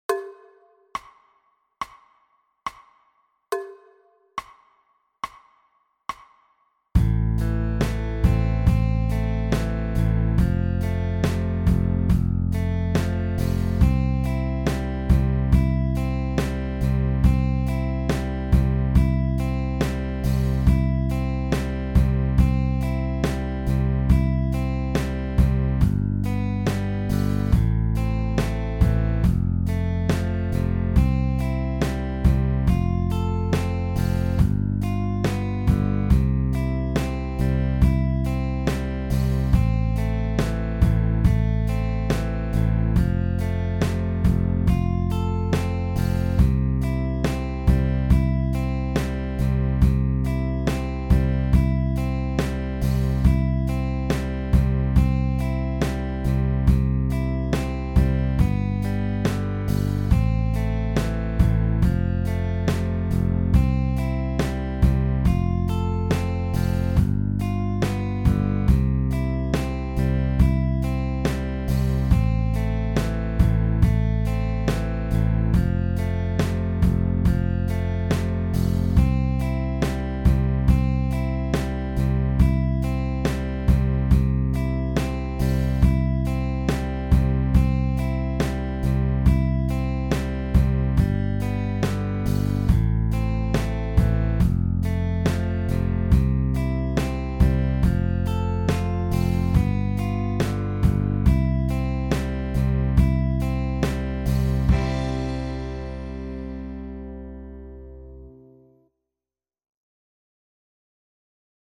Play-A-Longs
Solo in 70, 80, 90, 100 und 107 bpm